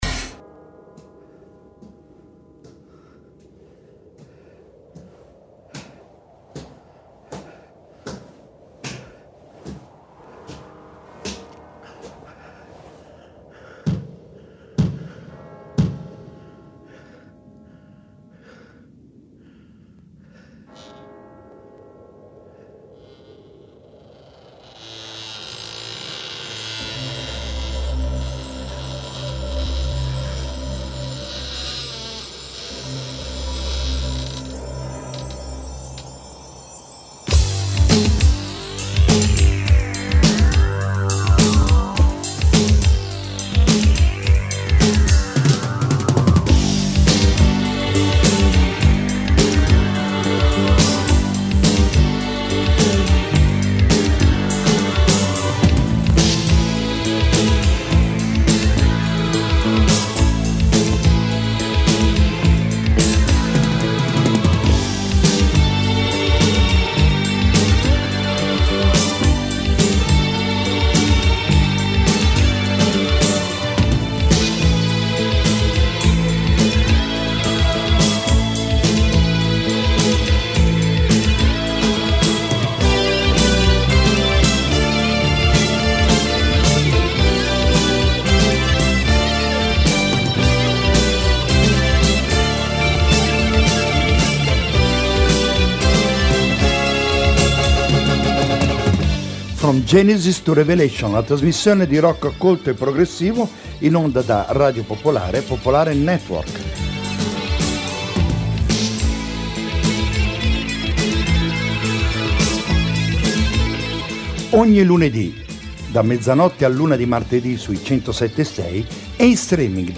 From Genesis to Revelation è una trasmissione dedicata al rock-progressive: sebbene sporadicamente attiva già da molti anni, a partire dall’estate...